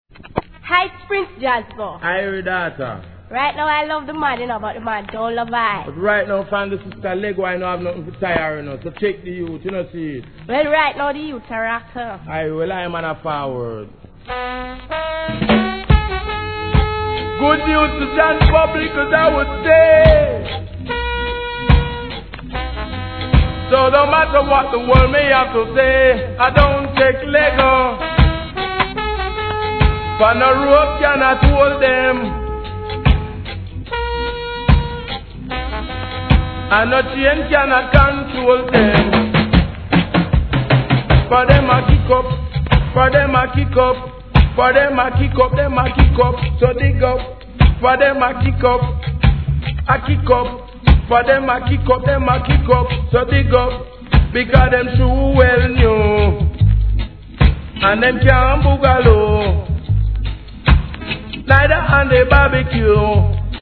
REGGAE
フォーンの印象的なイントロから太いベースが乗っかる名RHYTHM!